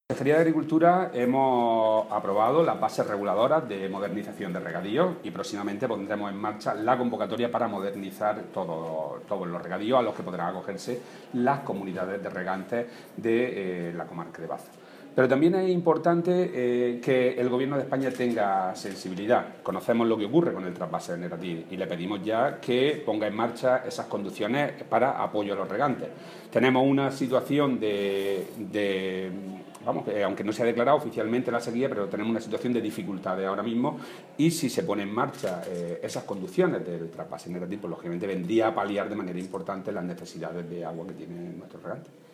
Declaraciones de Rodrigo Sánchez Haro sobre ayudas para modernización de regadíos y sobre recursos hídricos de Granada (audio)